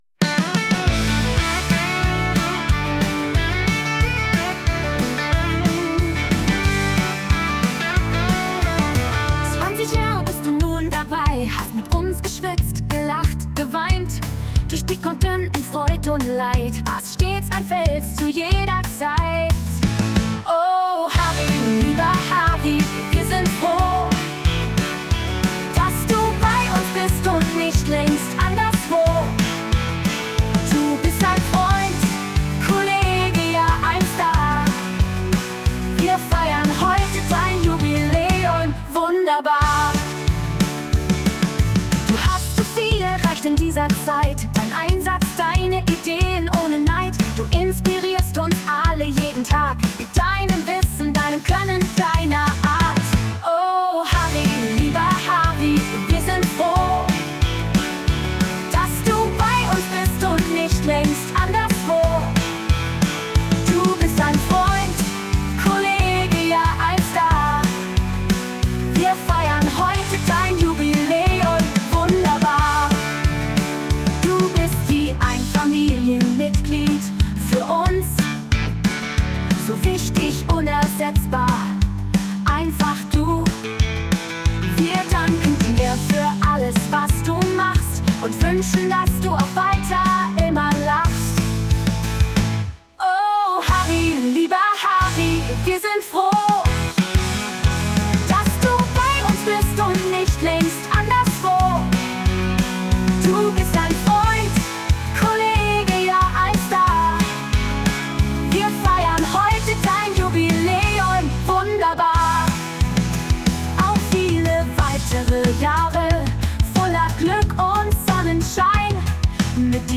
Jubiliäumsfeier